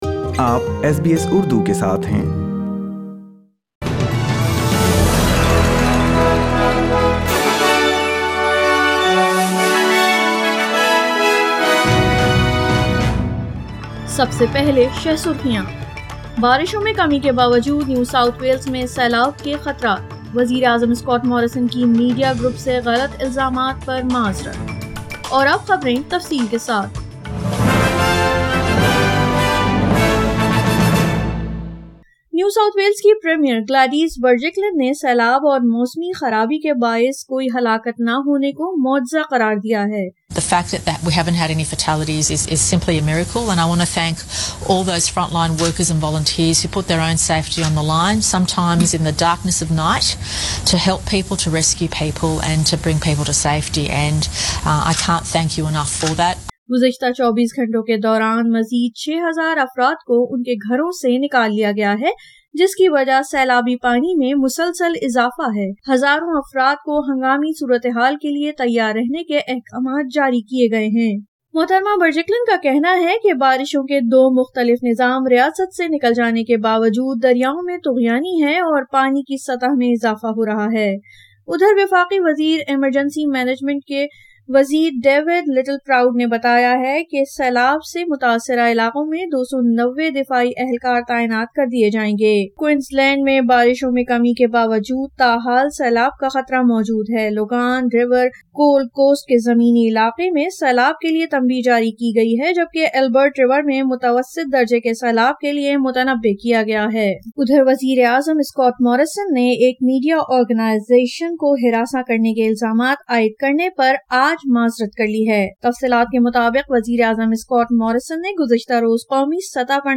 Urdu News Wed 24 March 2021